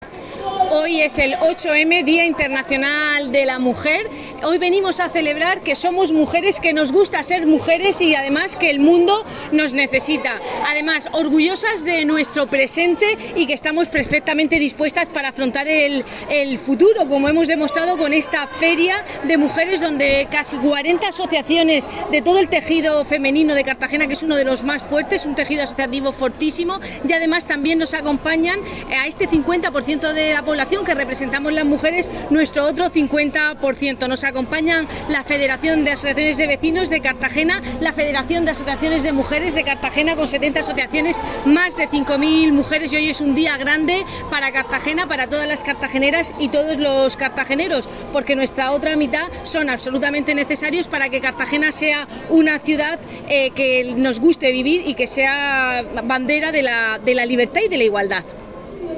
Enlace a Declaraciones de la edil, Cristina Mora, en el encuentro de asociaciones de mujeres.